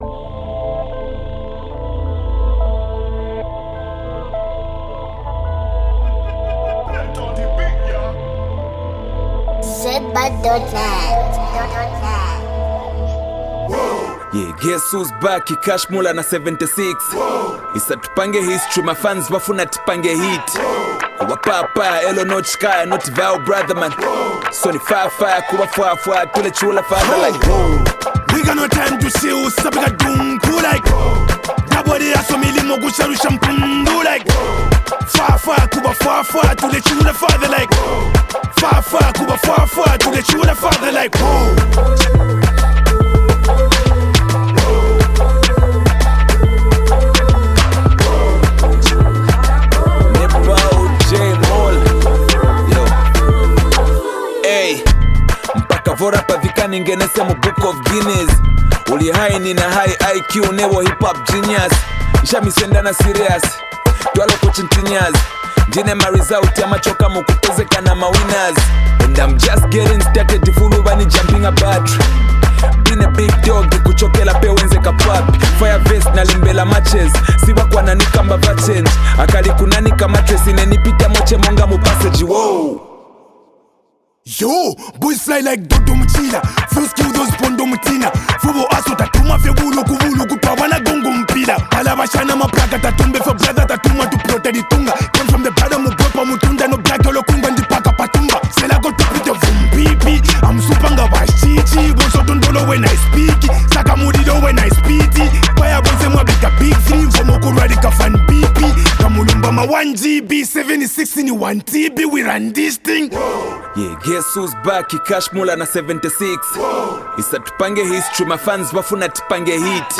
Genre: Afrobeats